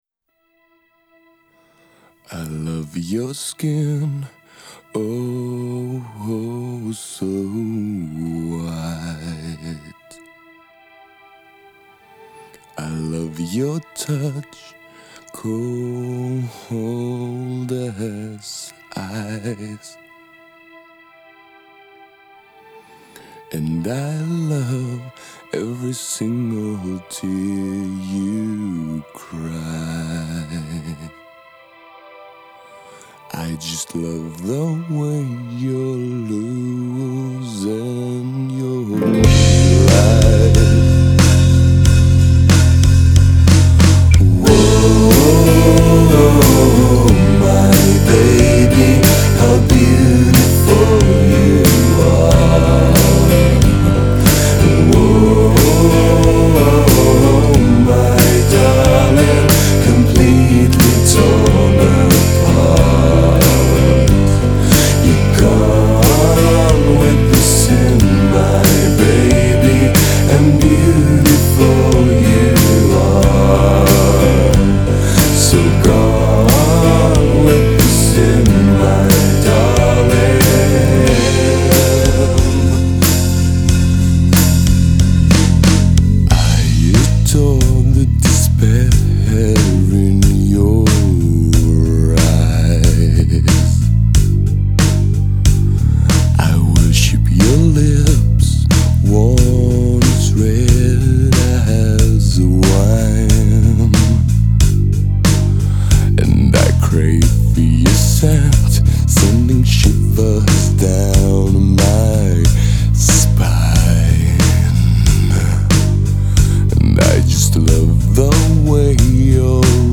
گوتیک راک
گوتیک متال